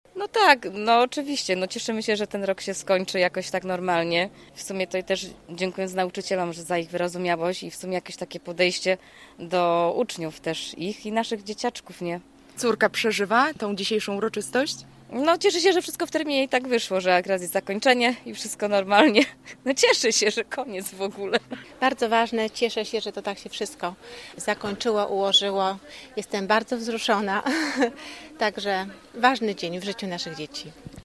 Wielu maturzystom towarzyszyli rodzice. Dla nich to także bardzo ważna uroczystość:
pozegnanie-absolwentow-rodzice.mp3